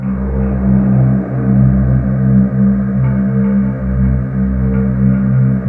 Index of /90_sSampleCDs/USB Soundscan vol.28 - Choir Acoustic & Synth [AKAI] 1CD/Partition C/12-LIVES